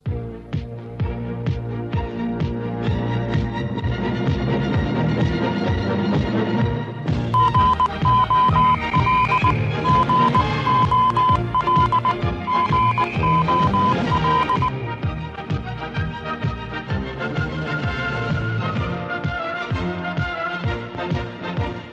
Sintonia del programa